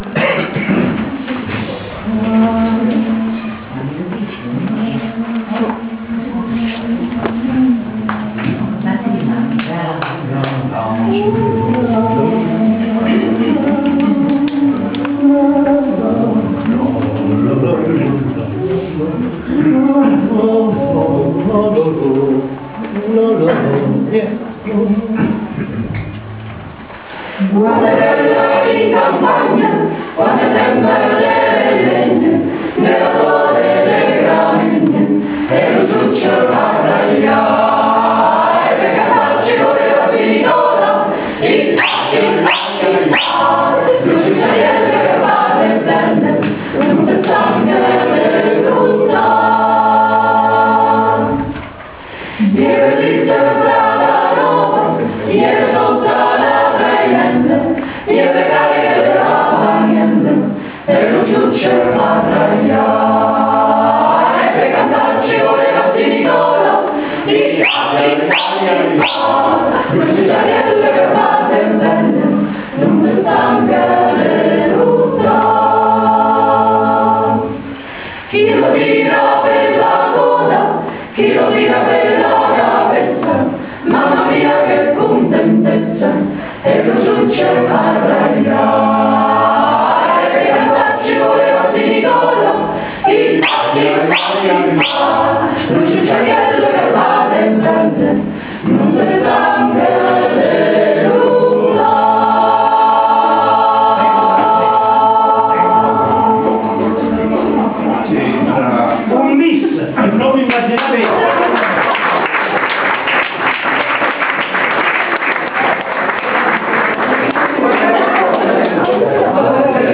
Civico Museo d’Arte Moderna di Anticoli Corrado, sabato 13 ottobre 2007